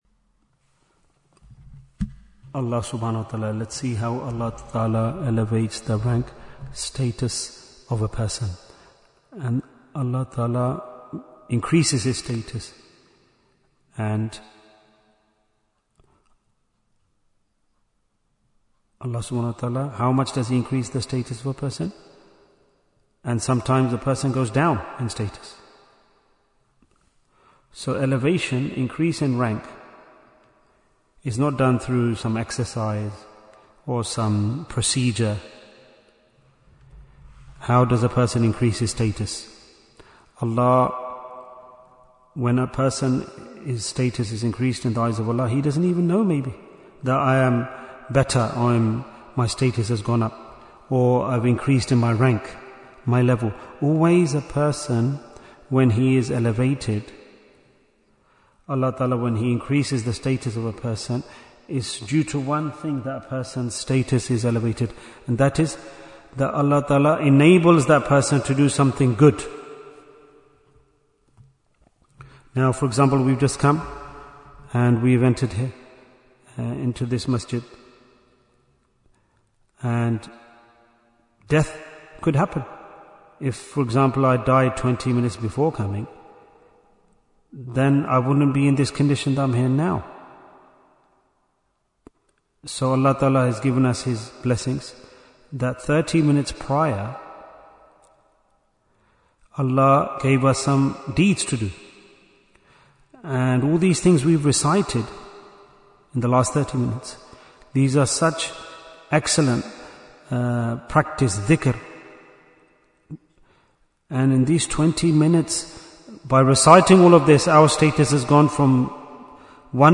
Jewels of Ramadhan 2026 - Episode 29 Bayan, 31 minutes11th March, 2026